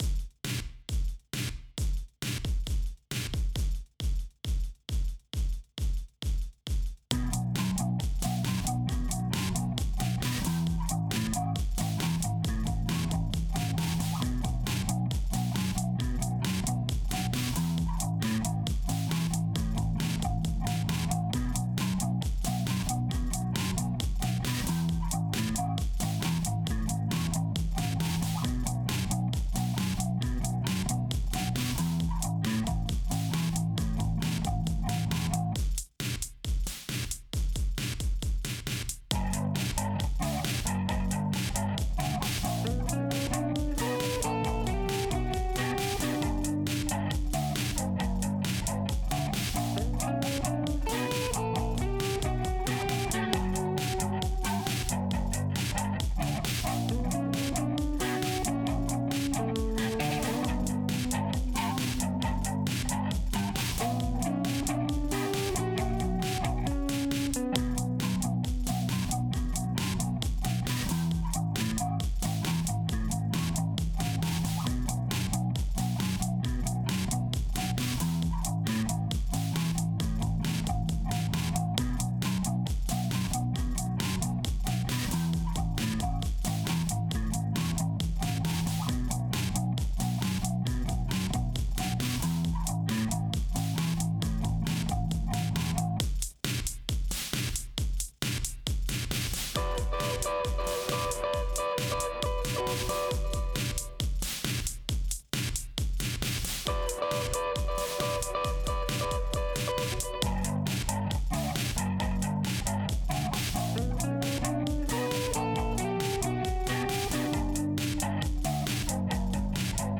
What can I say, I’m an instrumentalist, not a vocalist.